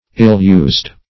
ill-used \ill`-used"\ adj.